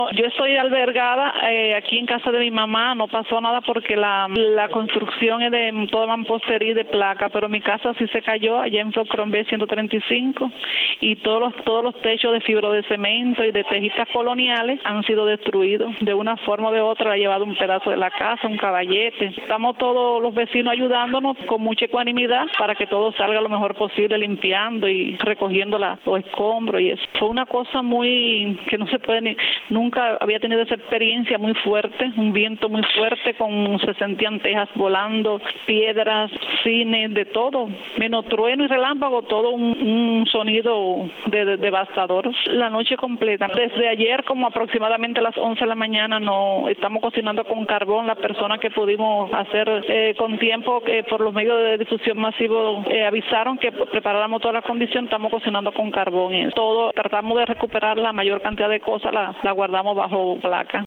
Residentes de Baracoa narraron a Radio Martí parte de lo que vivieron durante el paso del huracán Matthew, que dejó destrucción y tristeza en el extremo oriental de la isla.